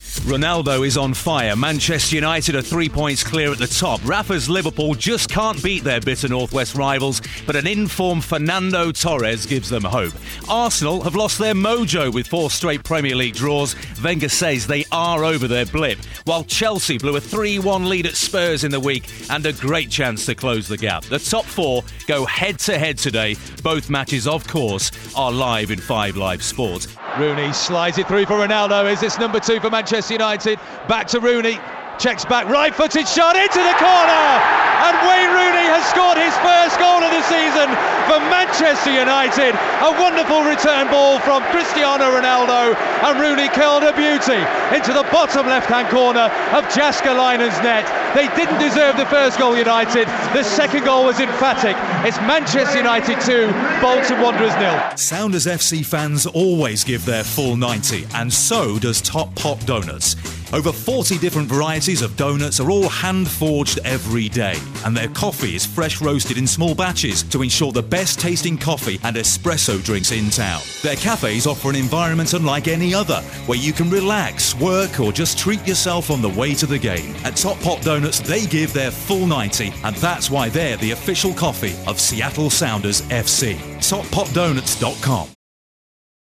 Narration
Commercial
Sports Commentator
arlo_white_voiceover_90secondsoundbite_commercial_1.mp3